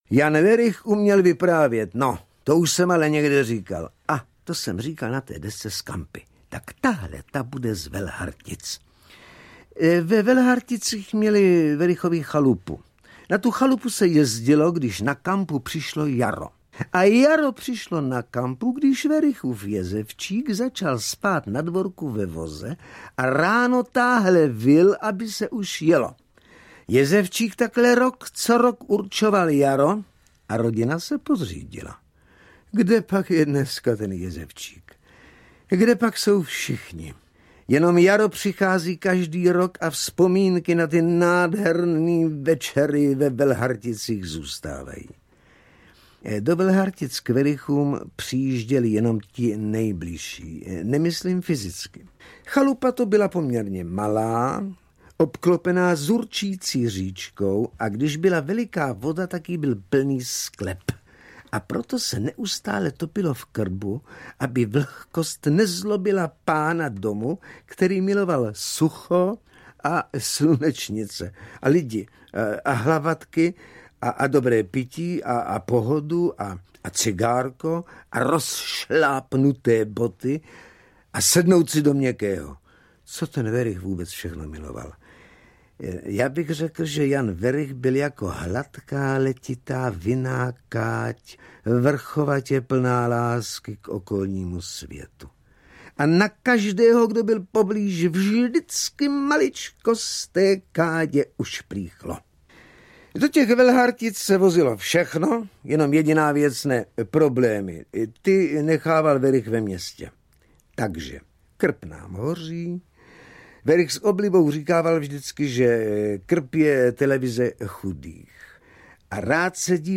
Forbíny života 2 audiokniha
Forbíny života 2 - audiokniha obsahuje vzpomínková vyprávění Jana Wericha. Účinkuje Jan Werich a Miloš Kopecký.
Ukázka z knihy